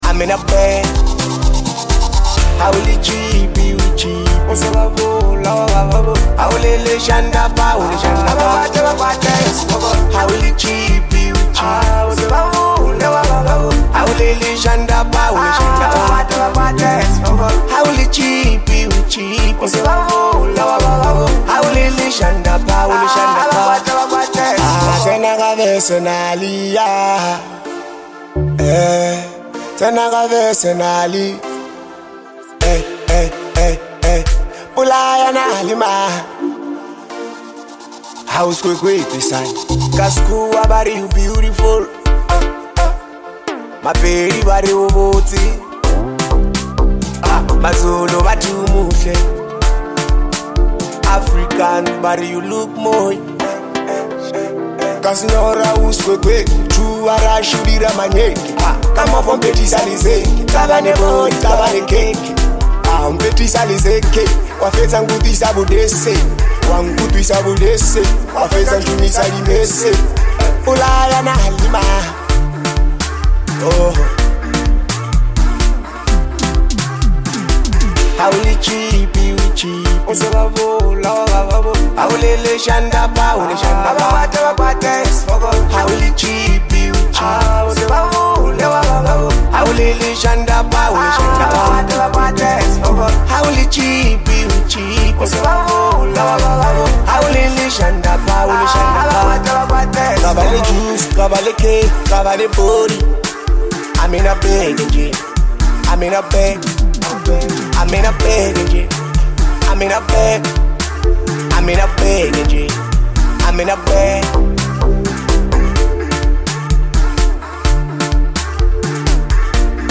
Award winning singer-songwriter